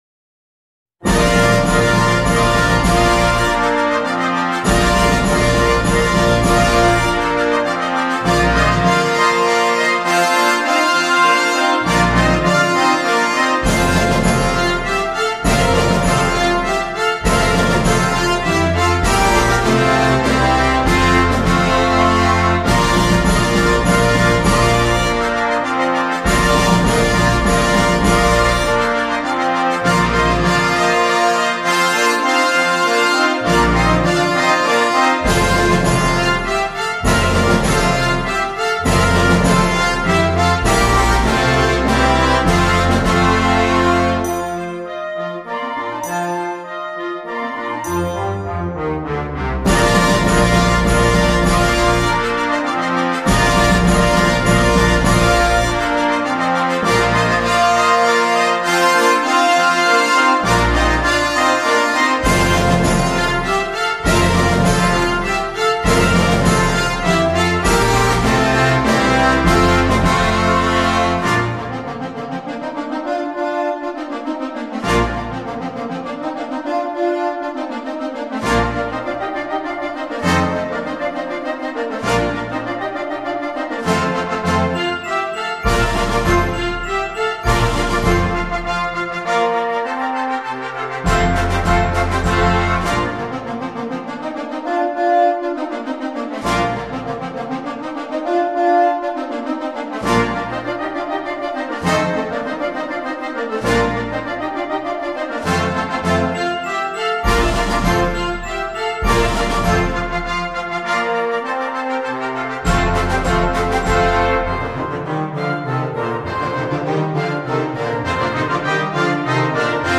KeyBb Major
CategoryBand Fanfare
Horns in F 1-2
Bb Trumpets 1-2-3
Timpani
Sleigh Bells
Glockenspiel